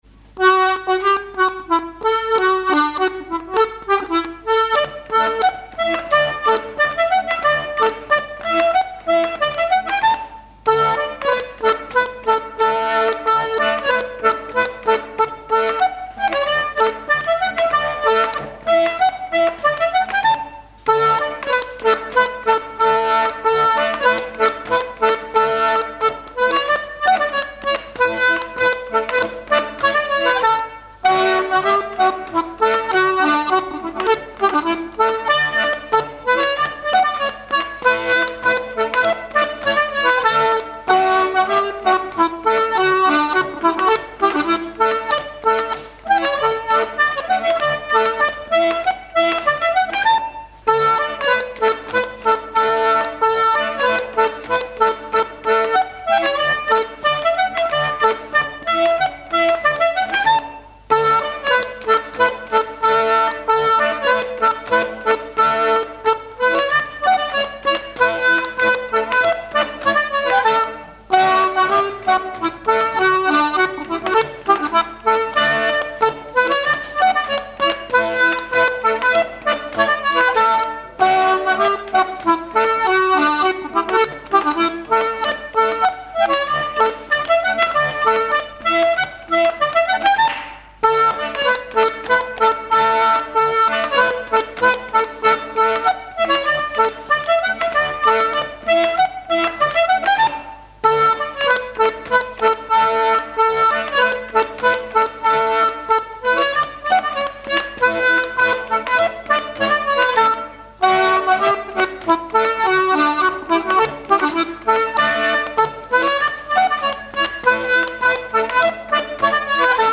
Mémoires et Patrimoines vivants - RaddO est une base de données d'archives iconographiques et sonores.
danse : pas d'été
airs de danse à l'accordéon diatonique
Pièce musicale inédite